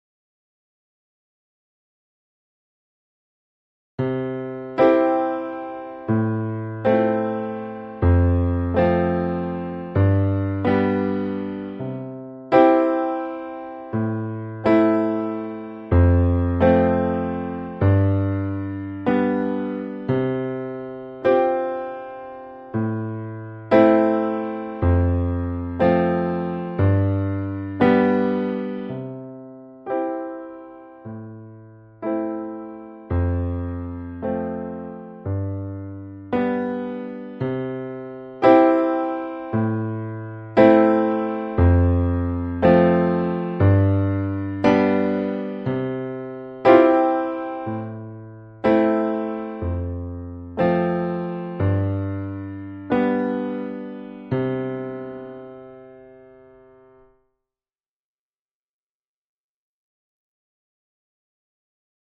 Piano: Day2
It’s not a great tune, just me hitting a bass note, then playing a chord to match (C, A, F, G). Timing is not great but what the hell.
piano1.mp3